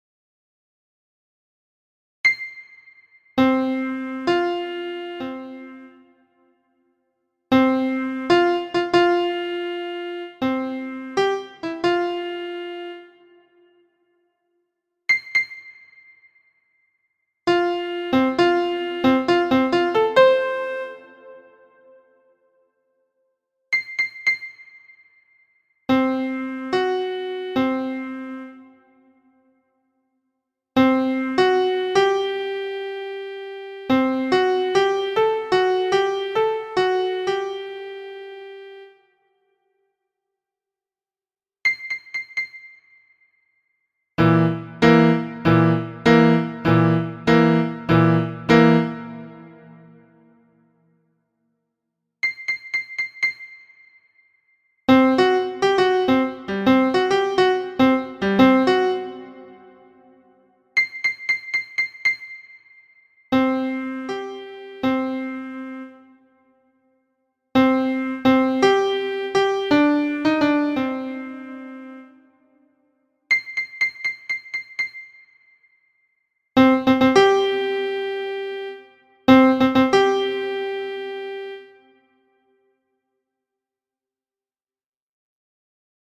This section contains some MIDI files that demonstrate some interval in the context of some songs.
Fourths and Fifths